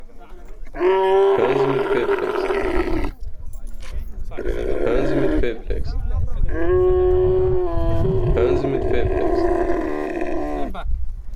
Dromedar Brüllen / Kamel
Bei einem Ausflug zu den arabischen Kamelen auf den Kanarischen...